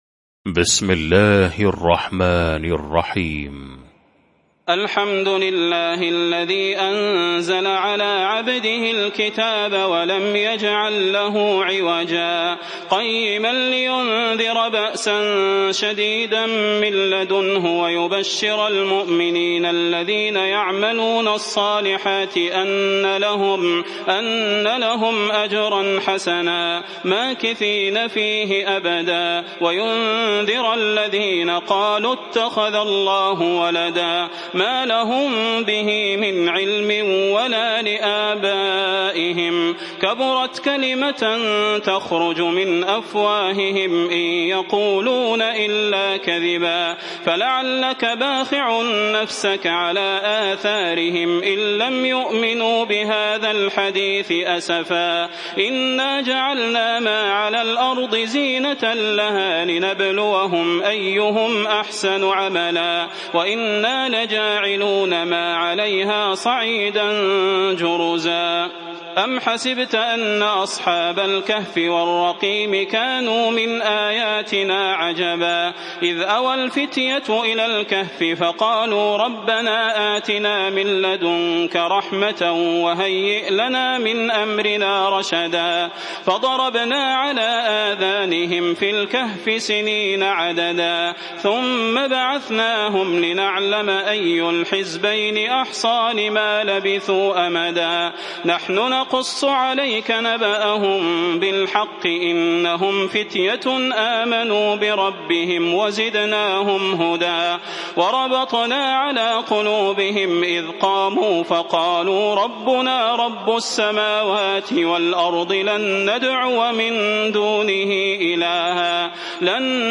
المكان: المسجد النبوي الشيخ: فضيلة الشيخ د. صلاح بن محمد البدير فضيلة الشيخ د. صلاح بن محمد البدير الكهف